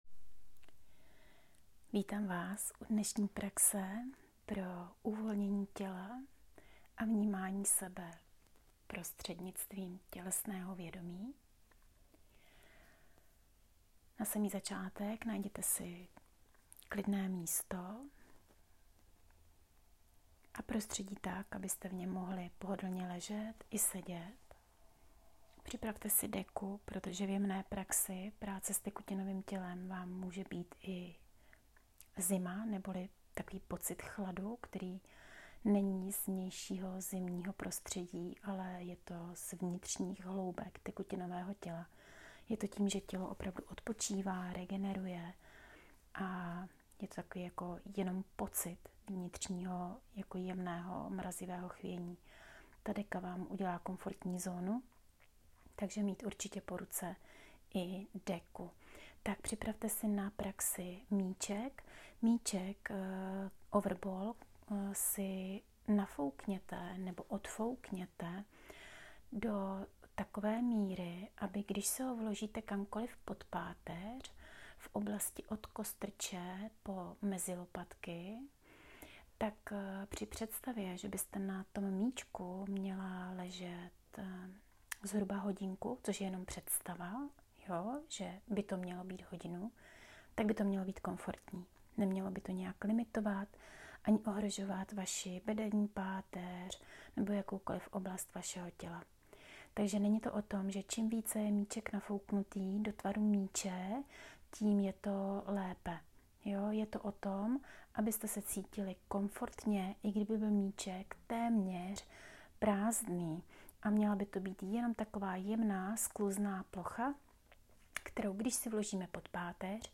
Na počátku vás provázím polohou jak si najít pozici, seznamuji s dvěma zvuky, které praxí a sebepéčí provází. Potom následuje příprava pro naladění na péči, uvolnění a odevzdání těla s důvěrou do náruče Země.
Až budete navedeni do praxe, doporučuji dát si pauzu v poslechu a případně si můj hlas pustit opět na závěrečnou fázi pro integraci.